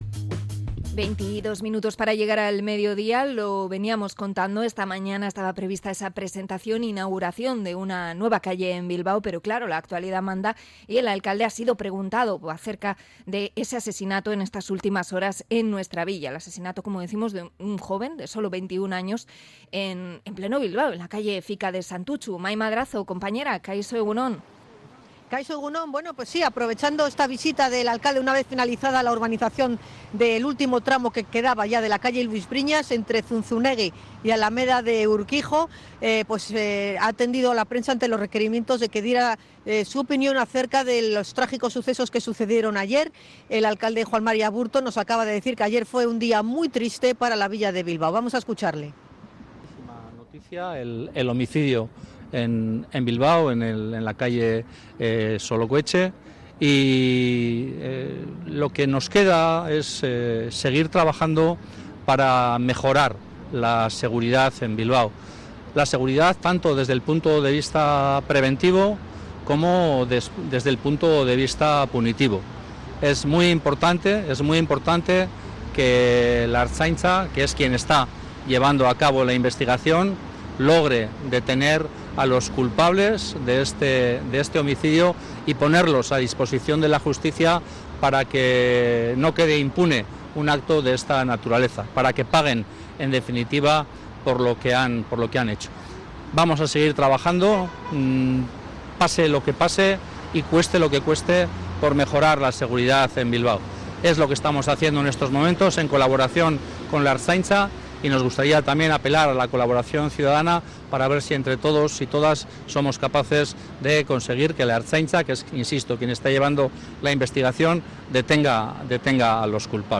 Juan María Aburto haciendo una declaración / Europa Pres
El alcalde, que ha realizado una valoración de los hechos ante los medios en la calle Luis Briñas en su visita a las obras de mejora y ensanchamiento de la zona peatonal, considera que la noticia fue «malísima desde el punto de vista de la seguridad».